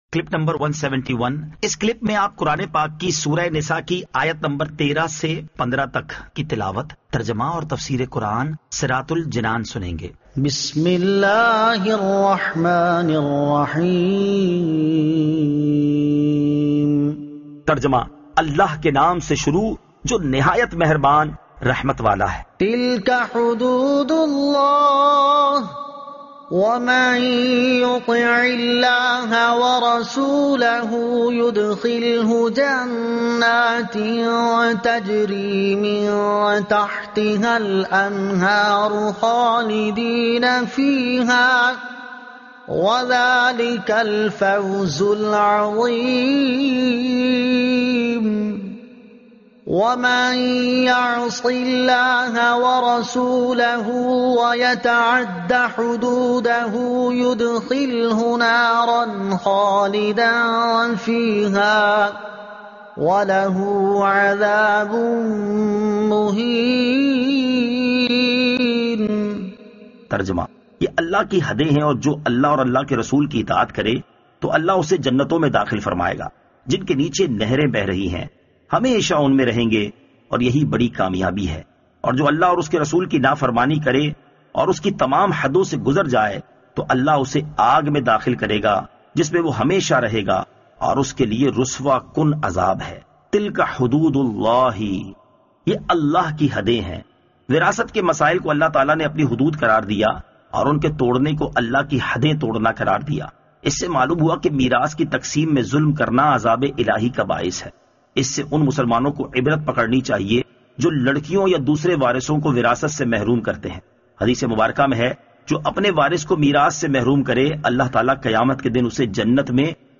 Surah An-Nisa Ayat 13 To 15 Tilawat , Tarjuma , Tafseer
2020 MP3 MP4 MP4 Share سُوَّرۃُ النِّسَاء آیت 13 تا 15 تلاوت ، ترجمہ ، تفسیر ۔